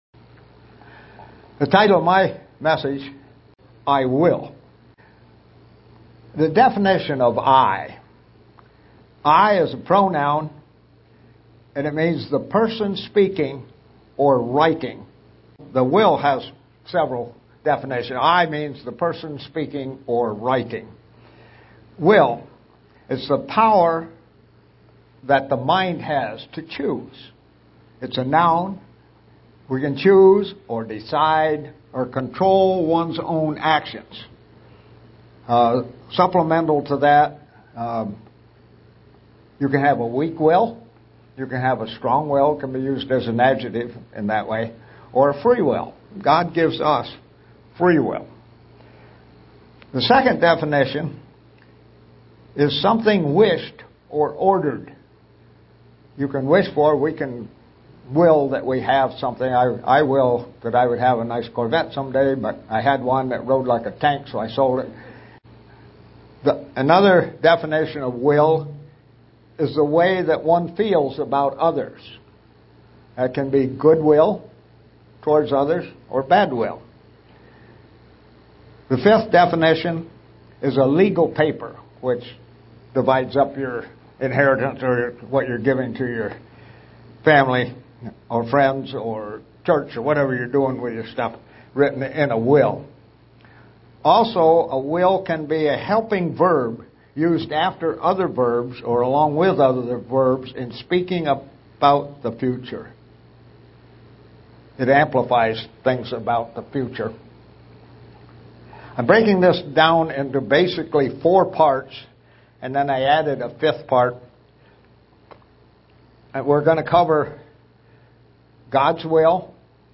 Given in Buffalo, NY
SEE VIDEO BELOW UCG Sermon